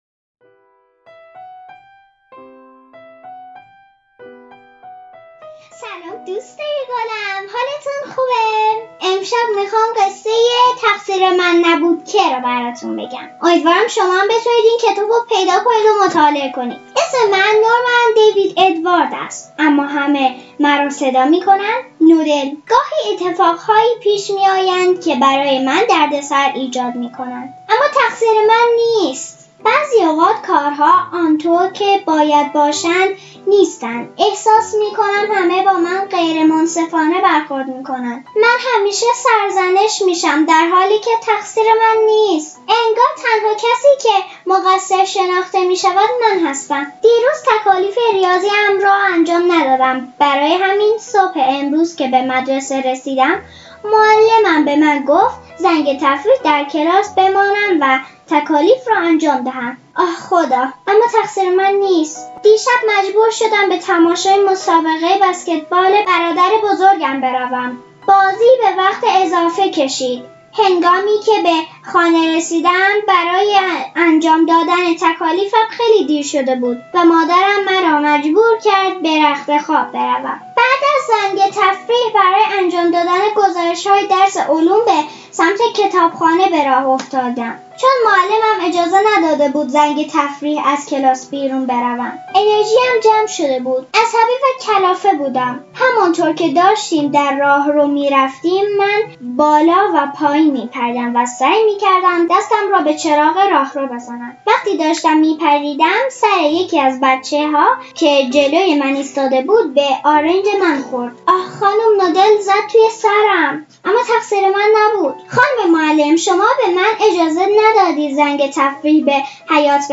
قصه کودکان
قصه صوتی